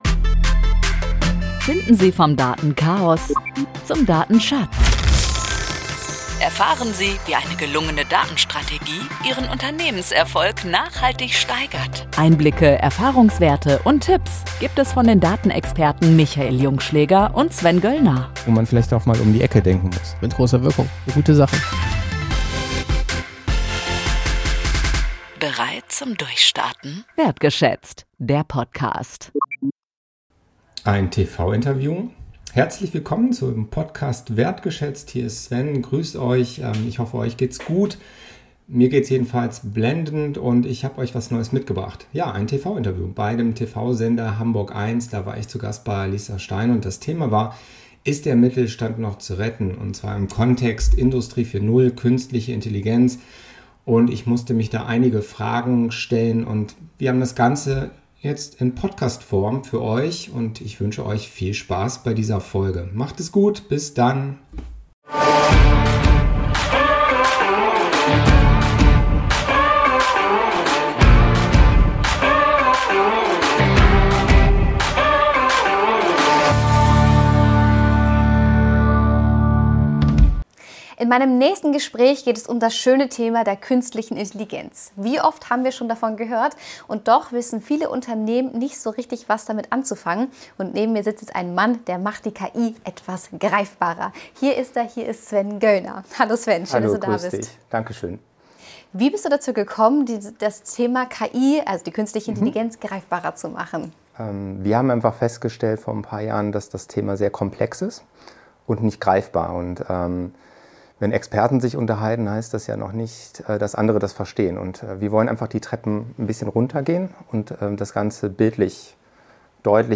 TV Interview